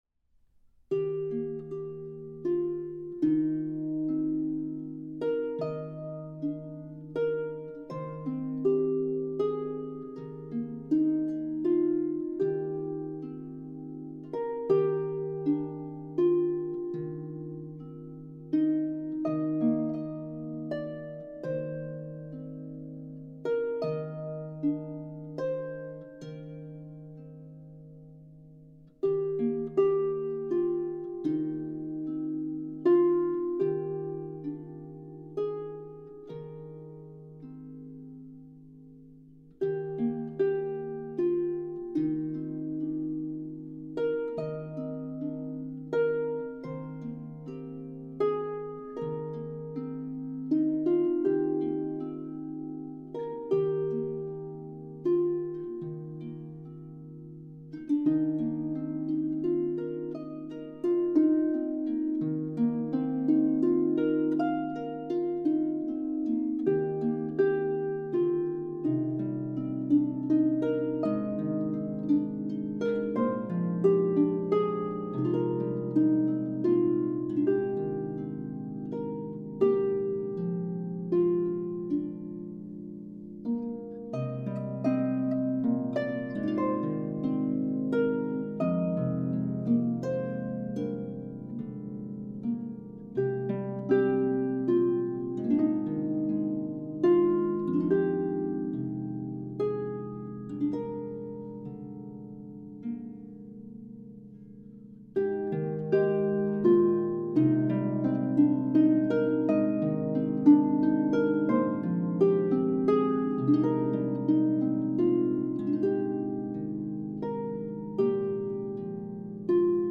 traditional French Christmas carol